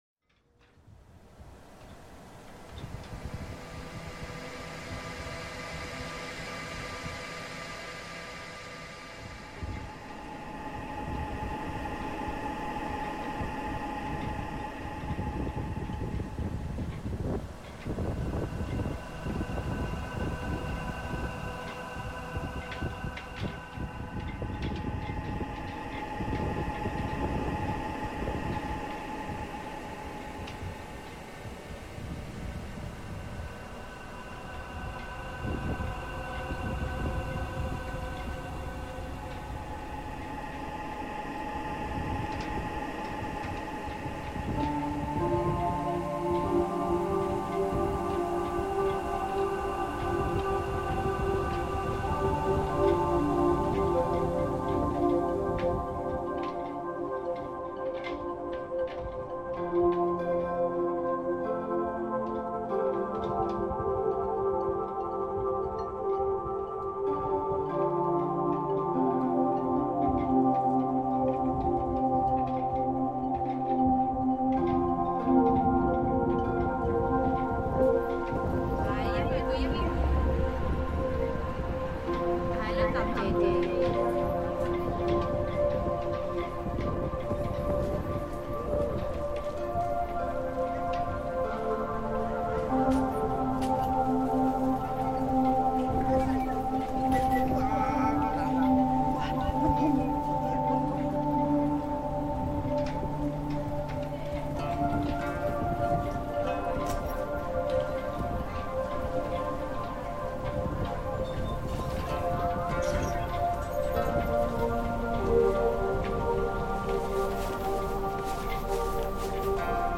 This work I am presenting makes an attempt at addressing these questions. It begins as though in a dream, imagine a voyage across a vast ocean and upon arrival, the surreal feelings of finally arriving and navigating through this new land ultimately finding a community of like people and the beginning of a new reality, a new life and a sense of place in this case all on the corner of Mott and Hester Streets in Chinatown, New York City.